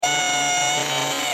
Play Robot Move - SoundBoardGuy
Play, download and share robot move original sound button!!!!
robot-move.mp3